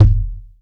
impact_deep_thud_bounce_08.wav